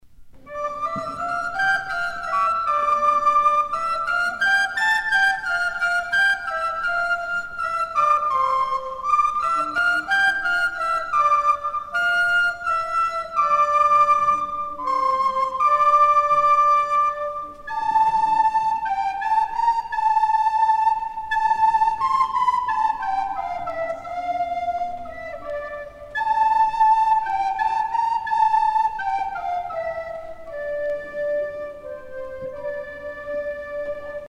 danse : tourdion (renaissance)
Pièce musicale éditée